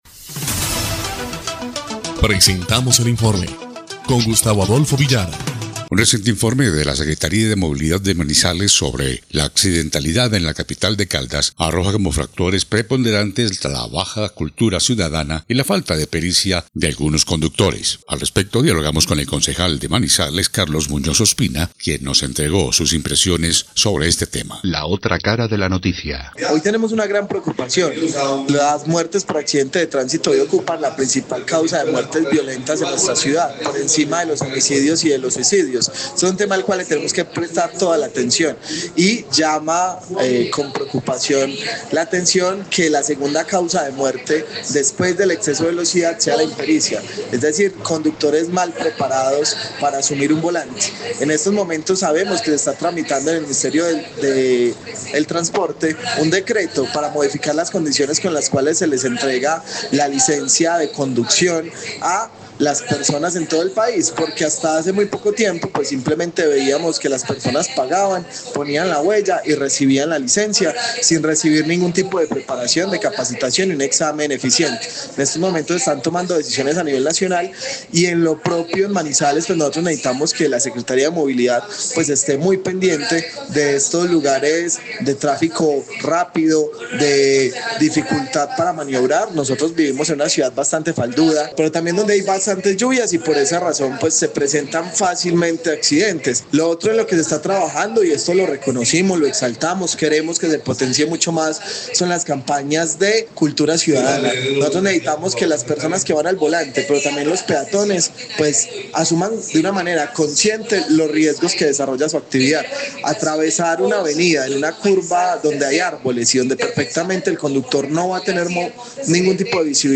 INFORME ESPECIAL – LA OTRA CARA DE LA NOTICIA